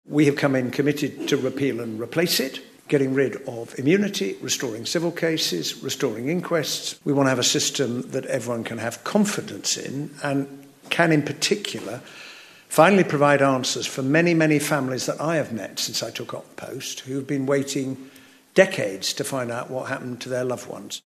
Secretary of State Hilary Benn says the Labour government in Britain will repair the damage done by its predecessors: